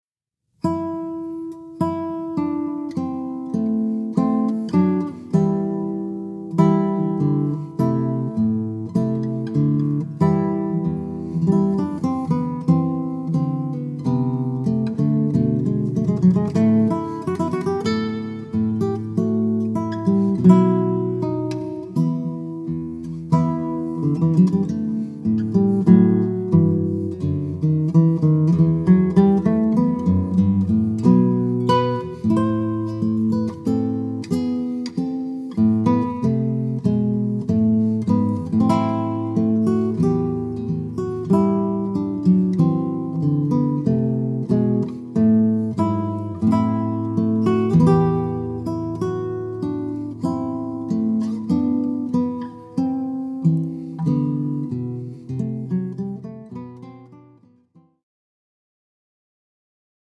Classical Guitar (Stereo recording) Recorded at Sac State